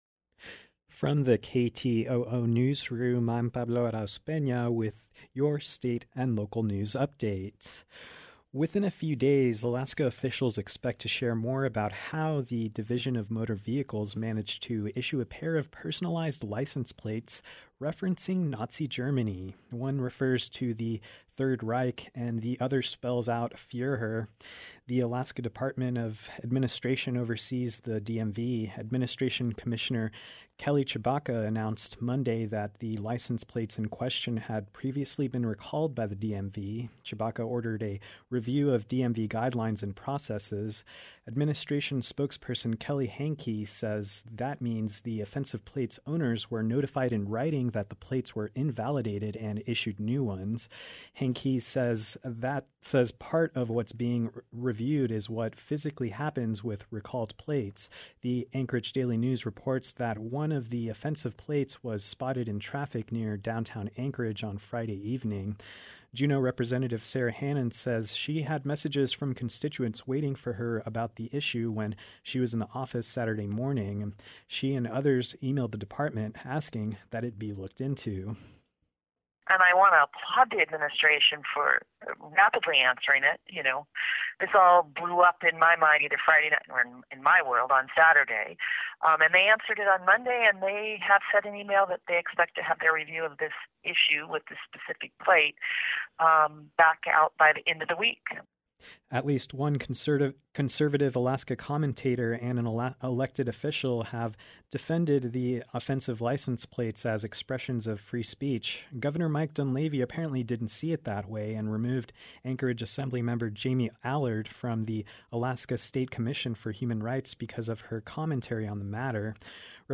Newscast – Wednesday, Jan. 27, 2021